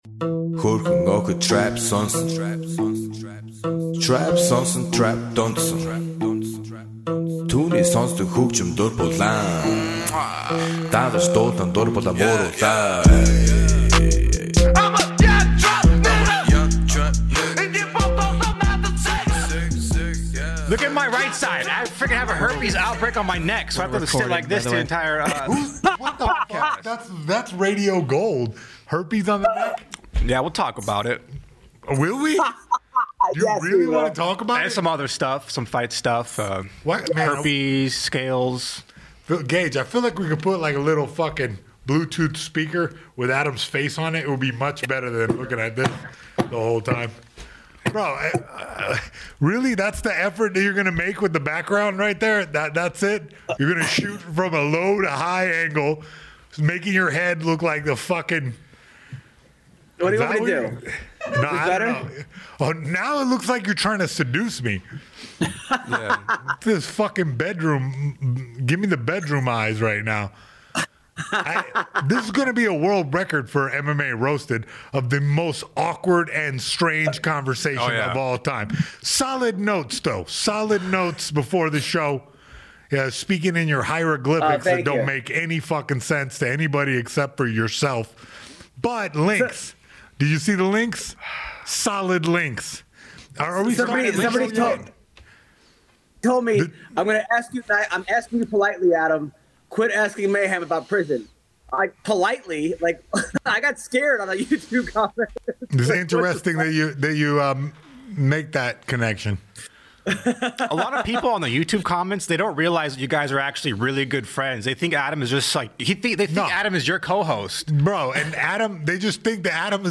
hold down the fort at Betterbox Studios